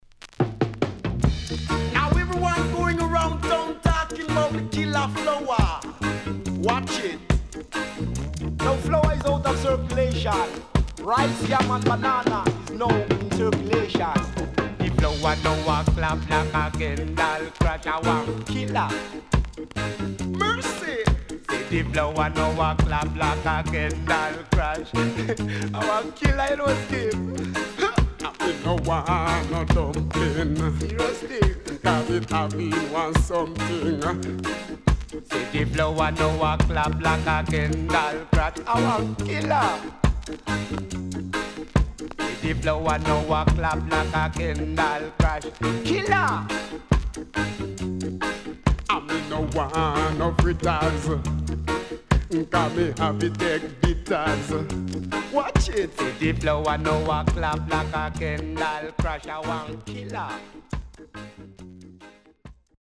76年 渋Vocal & Dubwise